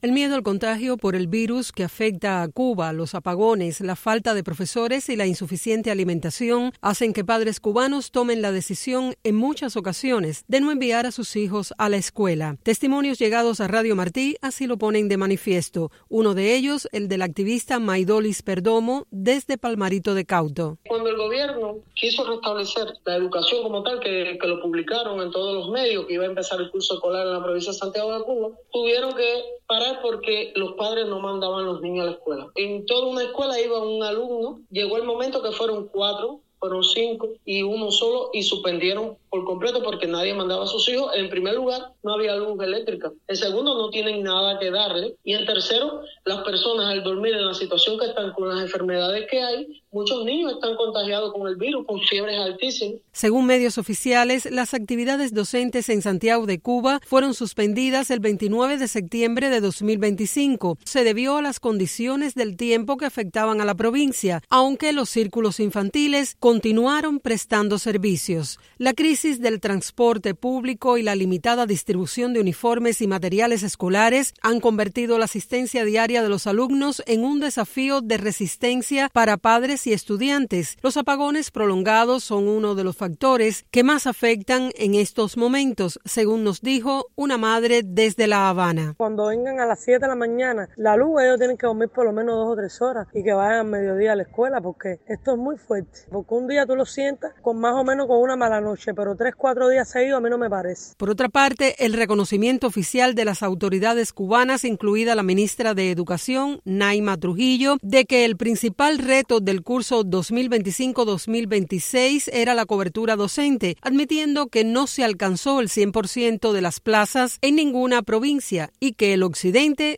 Testimonios recogidos por Radio Martí ponen de manifiesto esta situación.